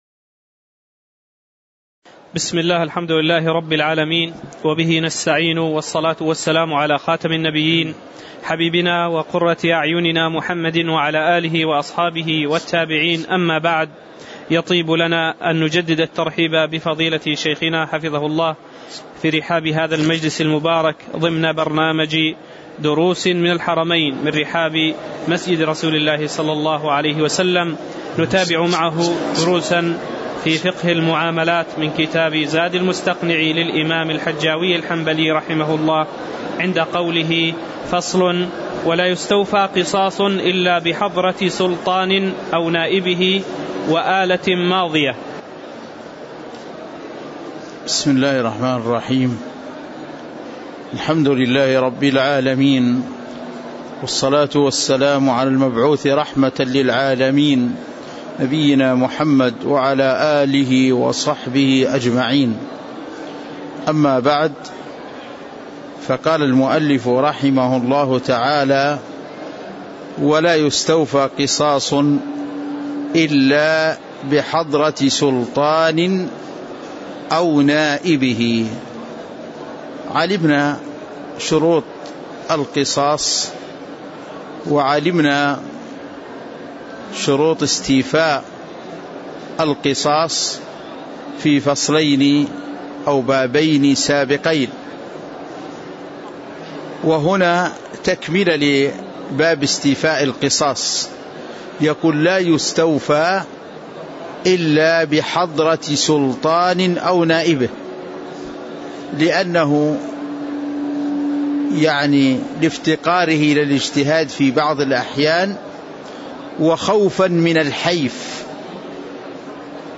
تاريخ النشر ١٢ ربيع الأول ١٤٣٨ هـ المكان: المسجد النبوي الشيخ